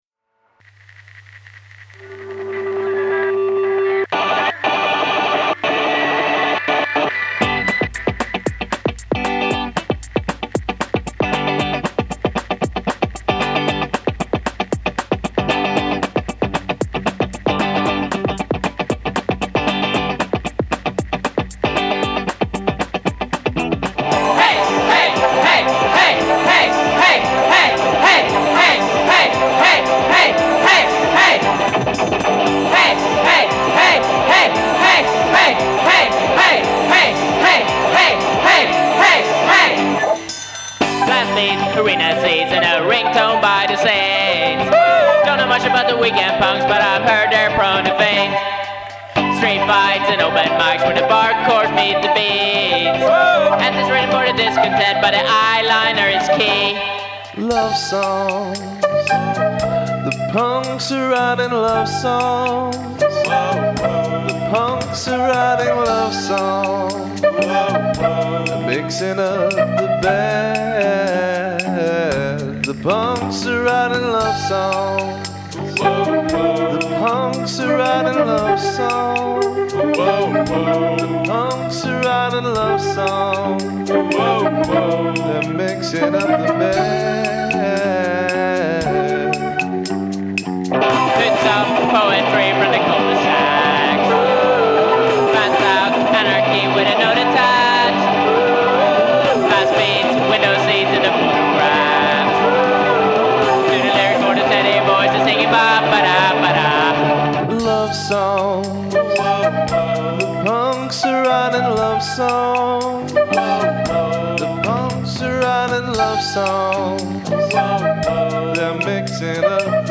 Today we have the duo